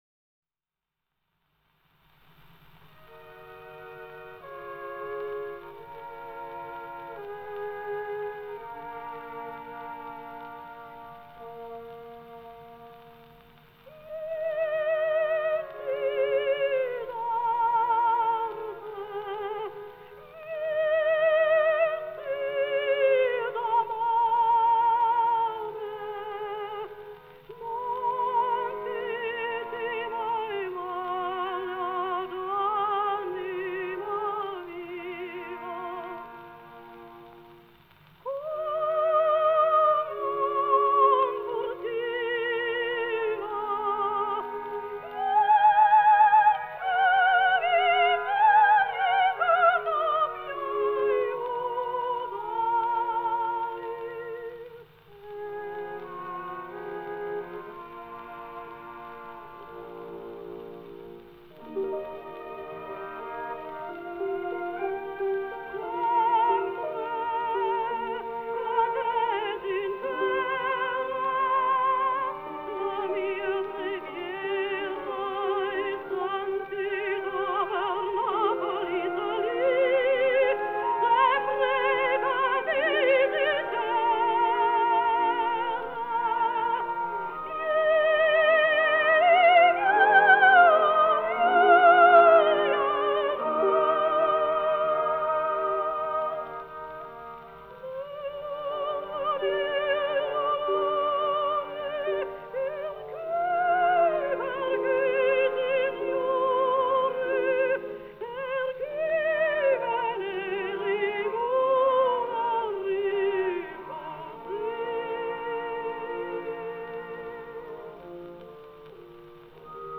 122 года со дня рождения немецкой певицы (сопрано) Элизабет Ретберг (Elisabeth Rethberg)